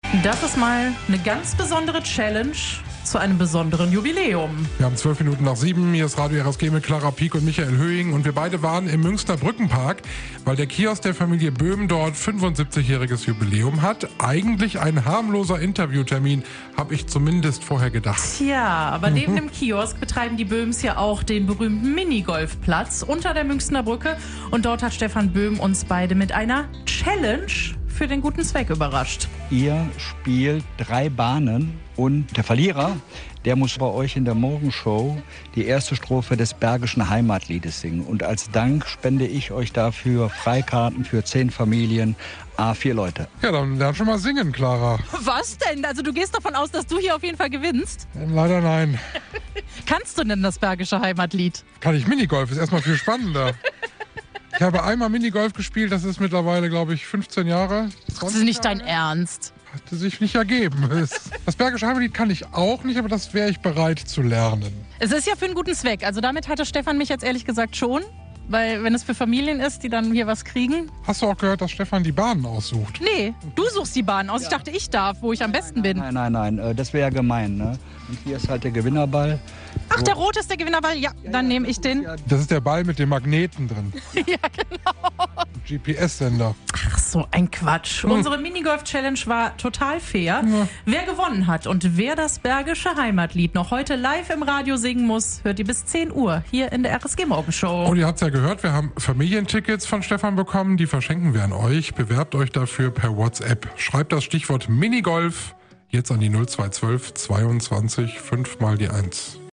Radio RSG vor Ort: Minigolf-Challenge zum Jubiläum